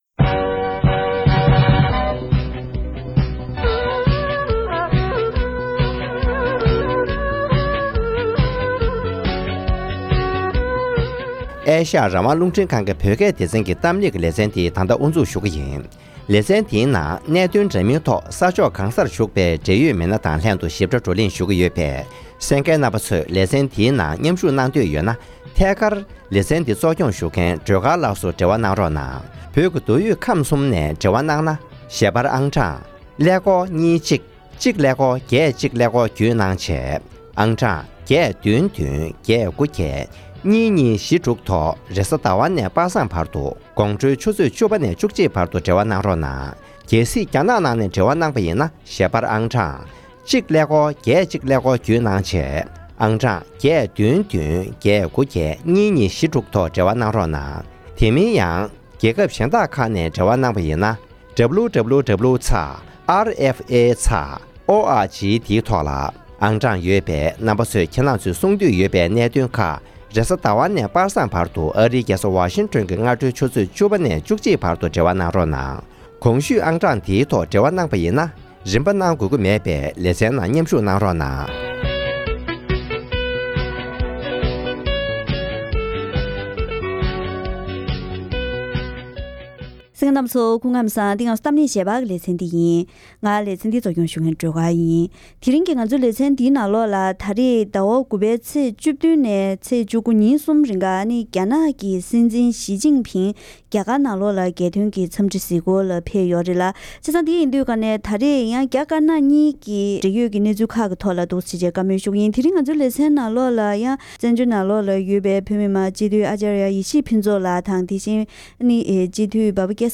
༄༅། །དེ་རིང་གི་གཏམ་གླེང་ཞལ་པར་ལེ་ཚན་འདིའི་ནང་། རྒྱ་ནག་གི་སྲིད་འཛིན་ཞིས་ཅིན་ཕིན་རྒྱ་གར་ལ་རྒྱལ་དོན་གྱི་འཚམས་འདྲི་གཟིགས་སྐོར་ལ་ཕེབས་པའི་ཁྲོད་ནས་རྒྱ་དཀར་ནག་གཉིས་ཀྱི་ཚོང་འབྲེལ་དང་ས་མཚམས་ཀྱི་གནད་དོན། དེ་བཞིན་བོད་ཀྱི་གནད་དོན་དང་བོད་དོན་ལས་འགུལ་སོགས་ཀྱི་ཐོག་འབྲེལ་ཡོད་དང་བཀའ་མོལ་ཞུས་པ་ཞིག་གསན་རོགས་གནང་།།